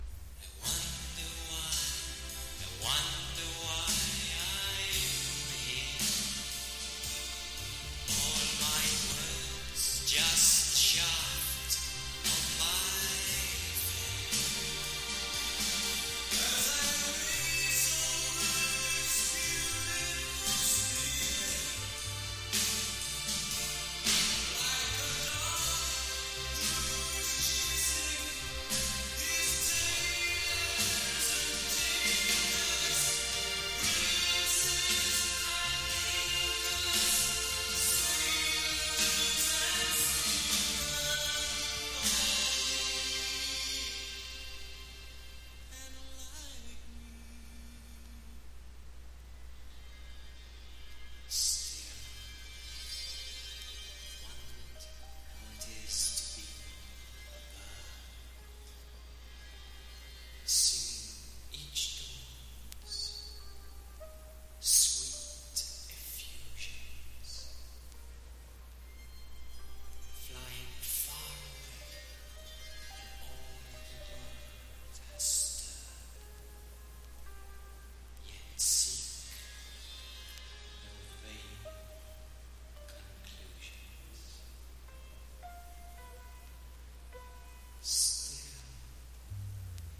英国の田園風景を思わせるような幽玄な音世界が広がっています。サイケナンバーもあり。